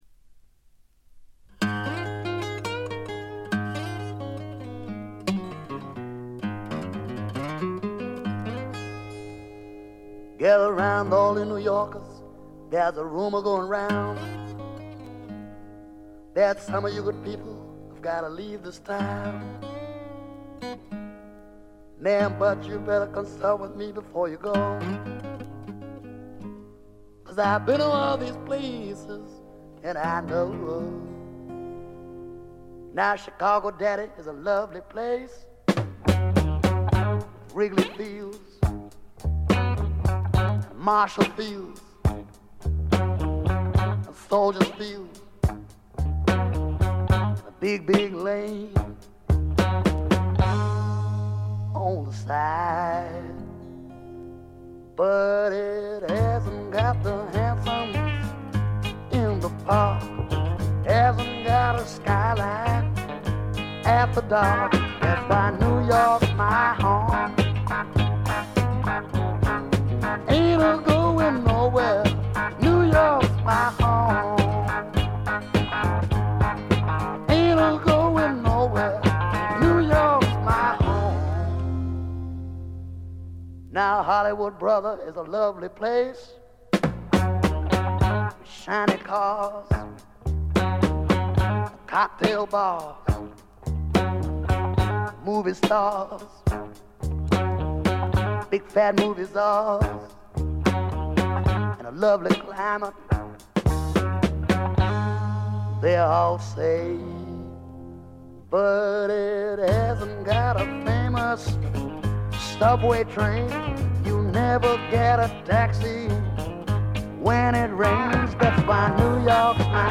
ごくわずかなノイズ感のみ。
試聴曲は現品からの取り込み音源です。
Recorded at:Nova Sounds and Island Studios
vocal and guitar